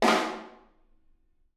R_B Snare Flam - Room.wav